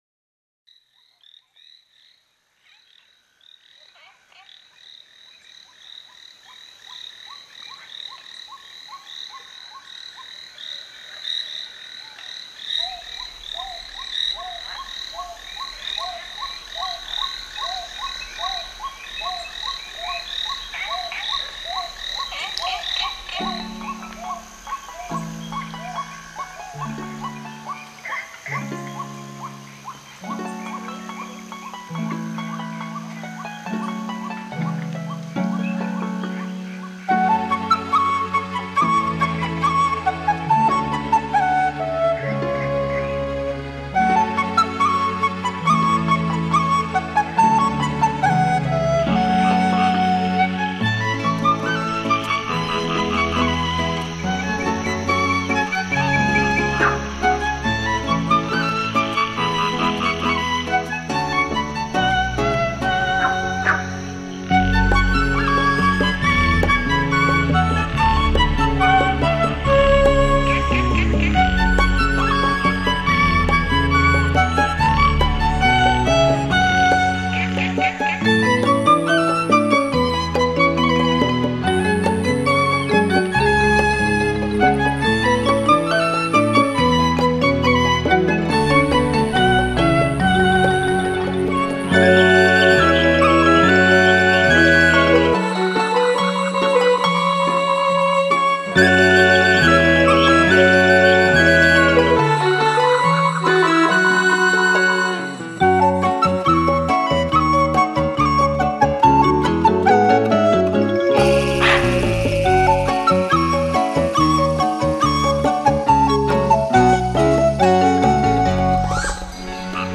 海浪、流水、鸟鸣，风吹过树叶，雨打在屋顶，大自然的原始 采样加上改编的著名乐曲合成了天籁之音。